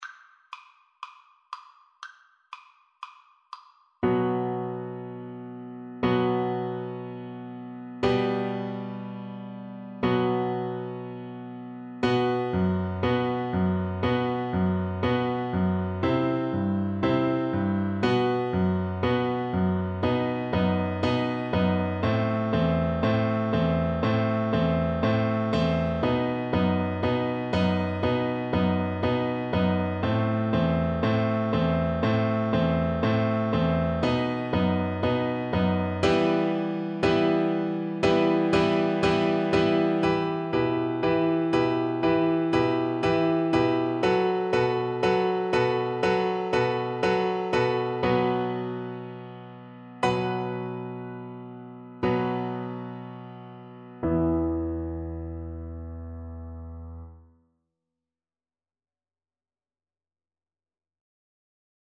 Double Bass
E minor (Sounding Pitch) (View more E minor Music for Double Bass )
Vivace (View more music marked Vivace)
4/4 (View more 4/4 Music)
Traditional (View more Traditional Double Bass Music)